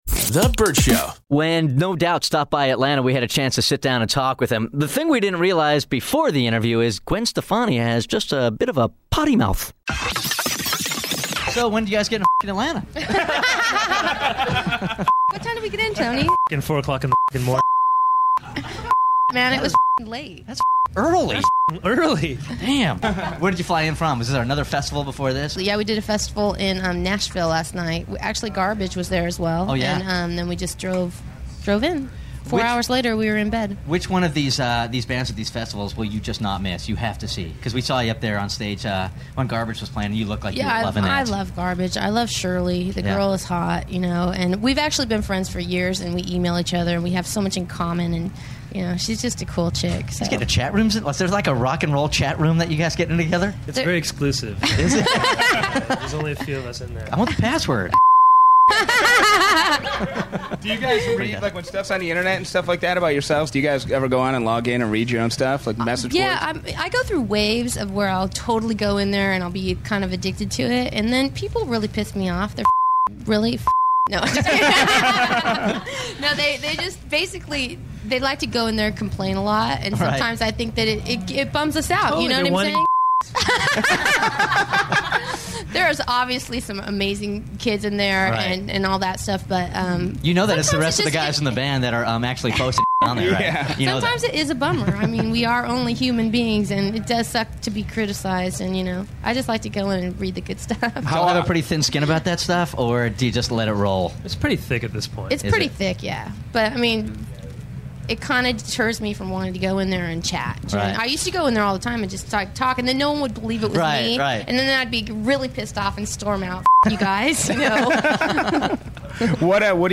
Vault: Interview With No Doubt